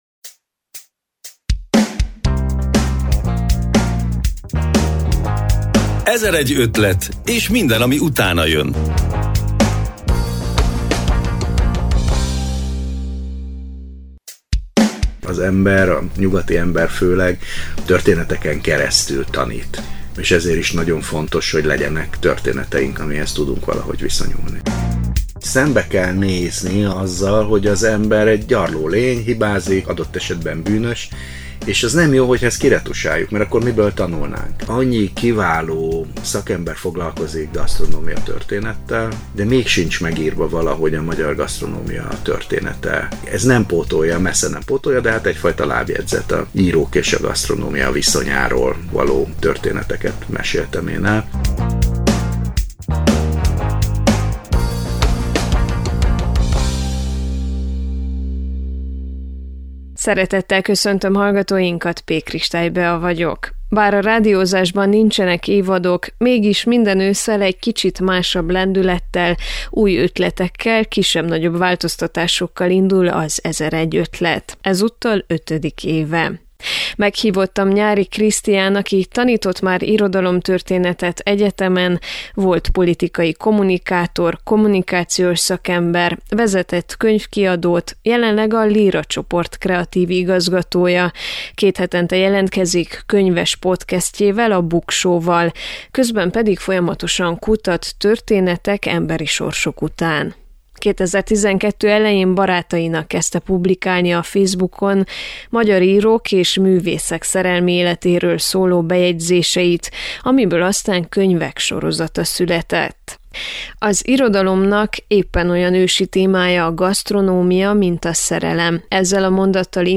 Az Így szerettek ők szerzőjével, Nyáry Krisztiánnal beszélgettünk történeteink megismerésének fontosságáról, szakmájáról és legújabb könyvéről is.